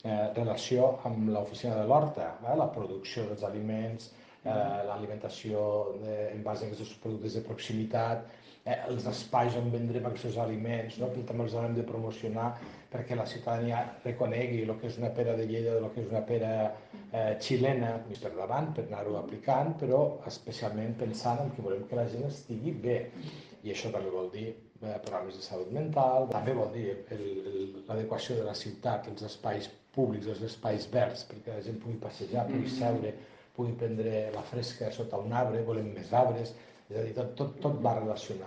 Larrosa ha explicat que el concepte ‘saludable’ engloba tots els vessants que convergeixen en la salut física i mental de la ciutadania, des de l’exercici físic, el descans o l’alimentació: TALL DE VEU ALCALDE LARROSA